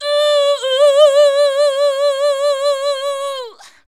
UUH-UUUUUH.wav